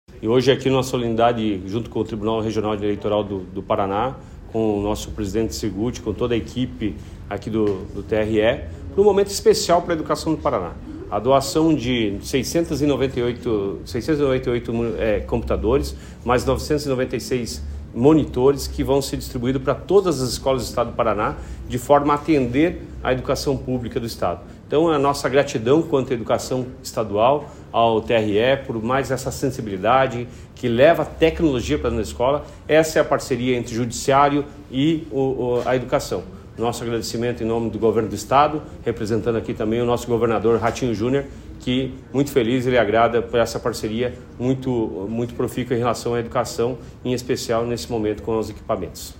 Sonora do secretário da Educação, Roni Miranda, sobre a entrega de computadores do Tribunal Regional Eleitoral para escolas da rede estadual